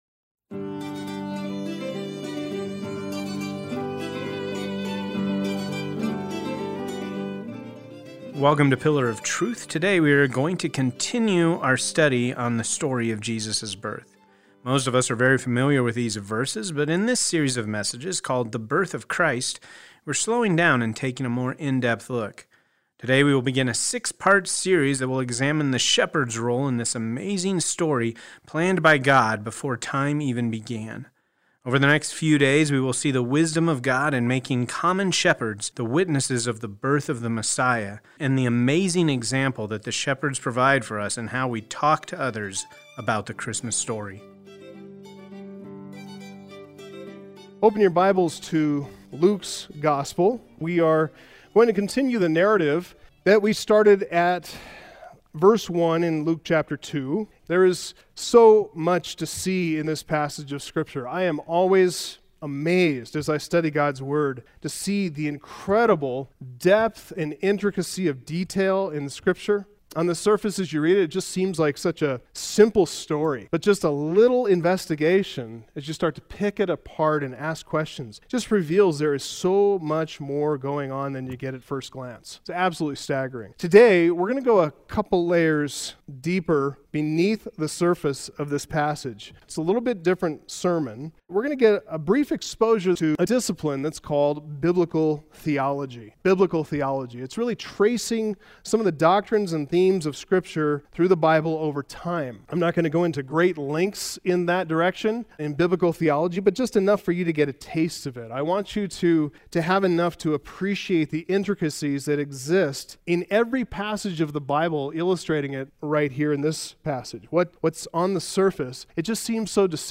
It’s a little bit different sermon.